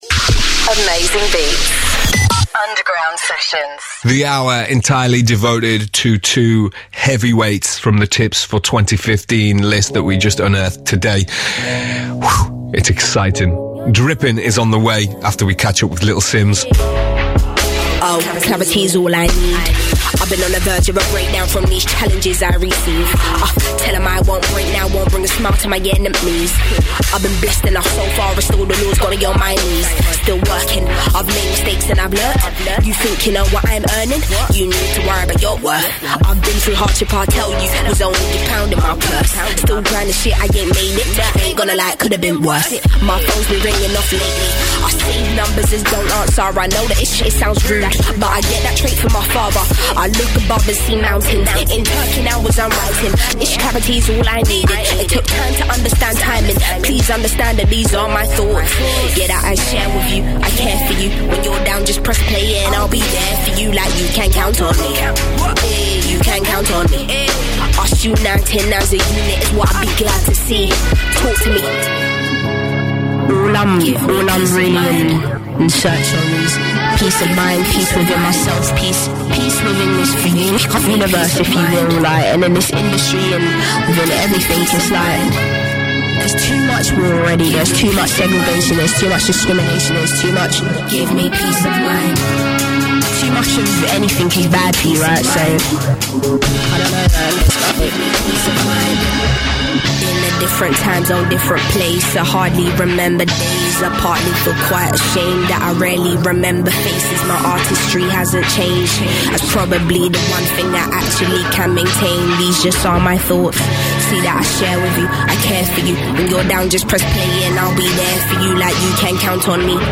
Tips For 2015 - Little Simz Interview